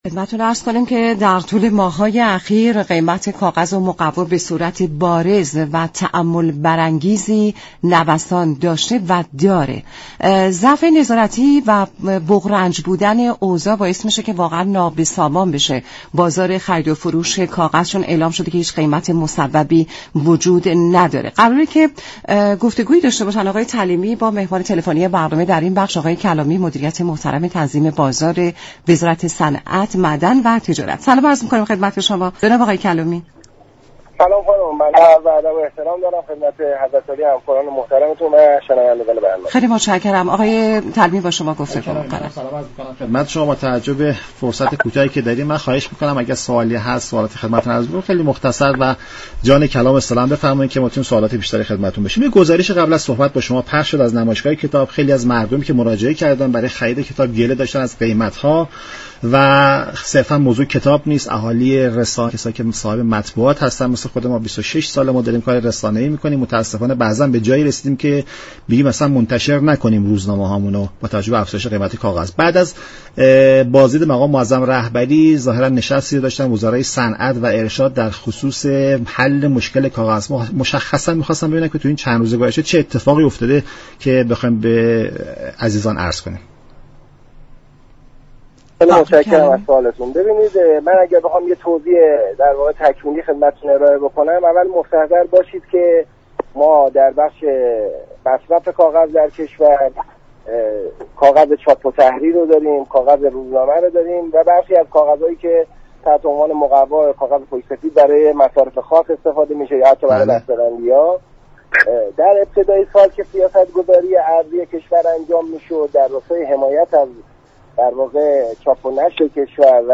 محمدرضا كلامی مدیر كل دفتر برنامه ریزی تامین، توزیع و تنظیم بازار وزارت صنعت،‌ معدن و تجارت در گفت و گو با "نمودار" به نوسانات بازار كاغذ اشاره كرد و گفت: سال 98 دولت در راستای حمایت از چاپ و نشر كشور و تولید كتاب با نرخ مناسب تر، قیمت كاغذ چاپ و نشر و روزنامه را با ارز 4 هزار و 200 مصوب كرد.